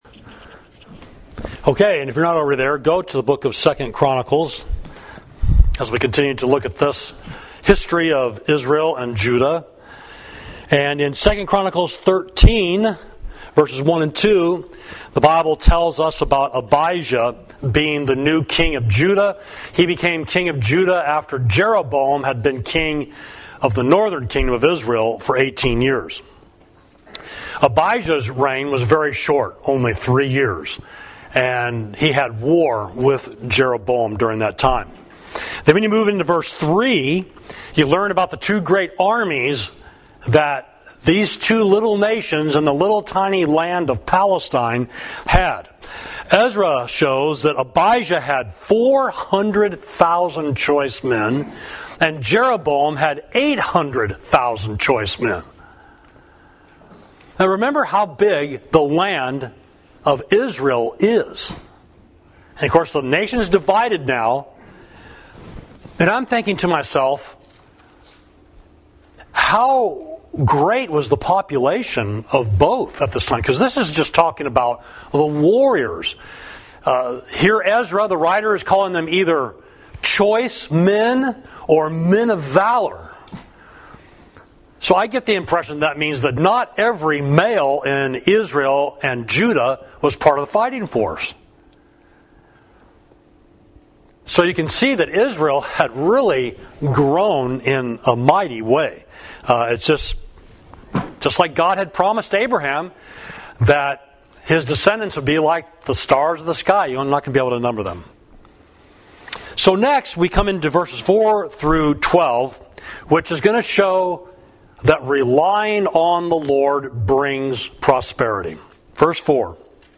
Sermon: Why Relying on the Lord Matters